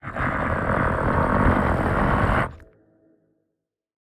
Minecraft Version Minecraft Version snapshot Latest Release | Latest Snapshot snapshot / assets / minecraft / sounds / mob / warden / angry_5.ogg Compare With Compare With Latest Release | Latest Snapshot
angry_5.ogg